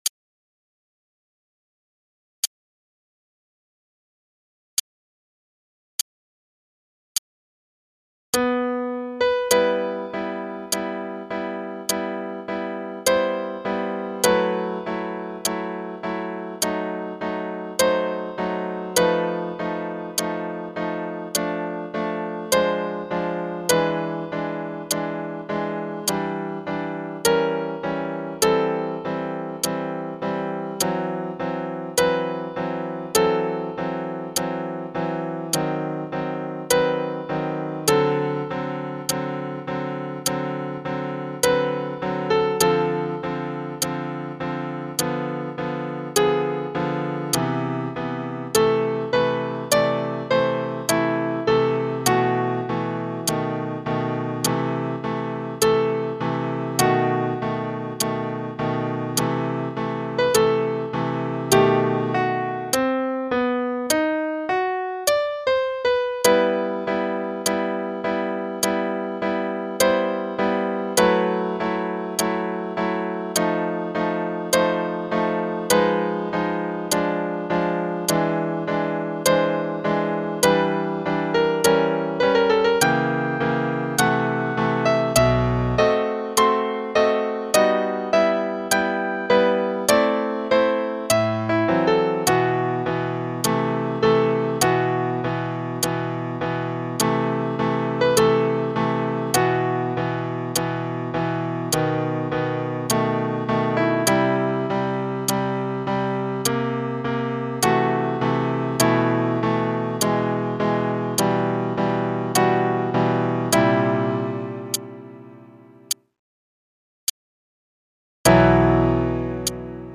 Play-a-long_Chopin - Prelude Op28No4, no click qn=52.mp3